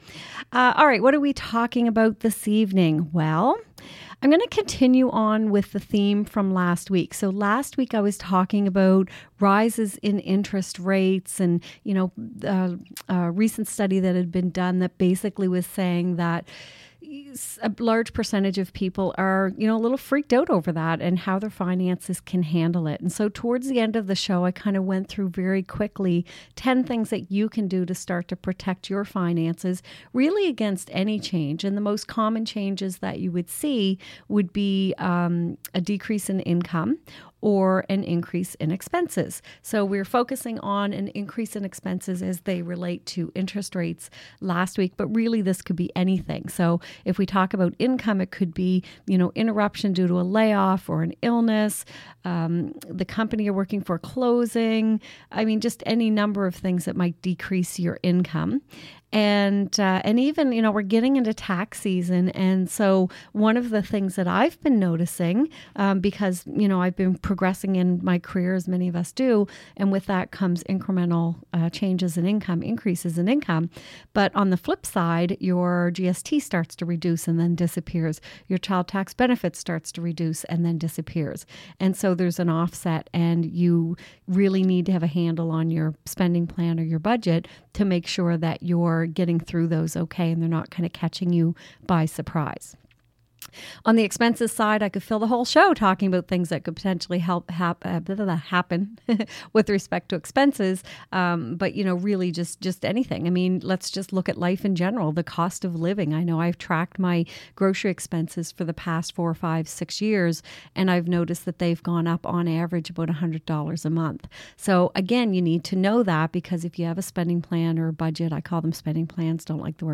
Radio Show Podcasts
[It took some time for me to figure out how to record my shows, the older ones are “raw” recaps…you never know what you will hear ;)]-